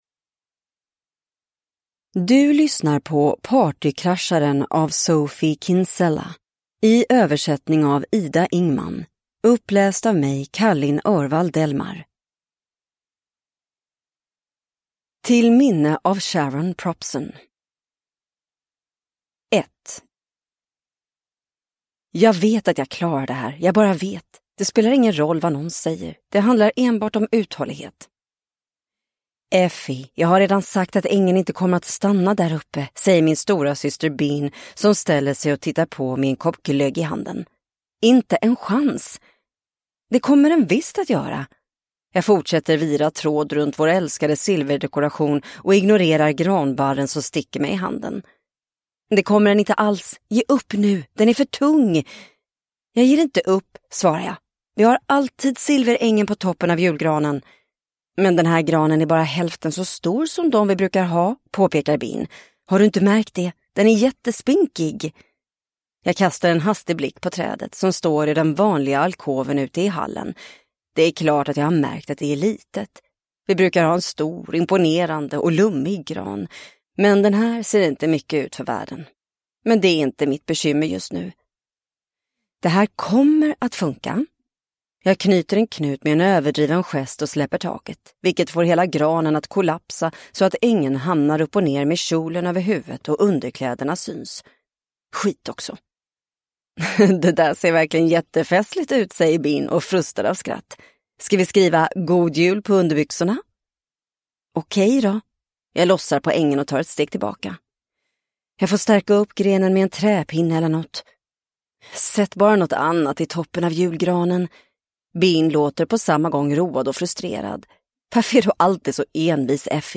Partykrascharen – Ljudbok